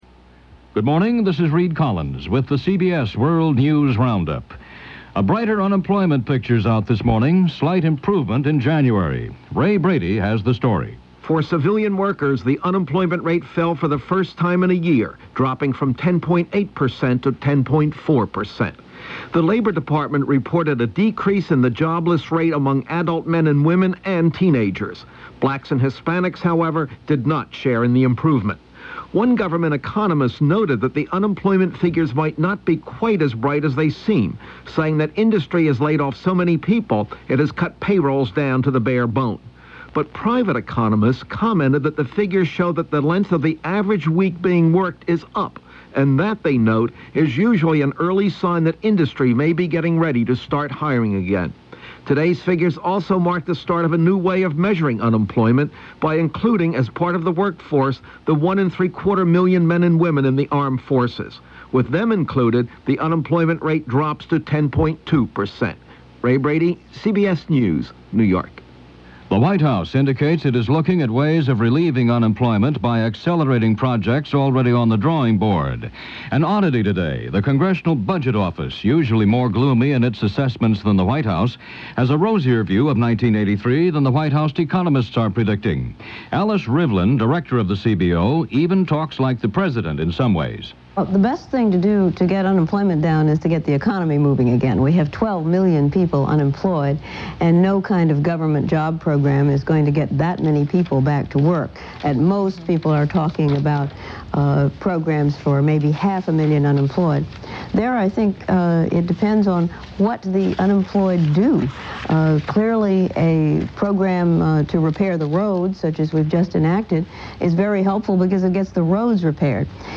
And that’s a little of what went on, this fourth day of February in 1983, as presented by The CBS World News Roundup.